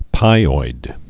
(pīoid)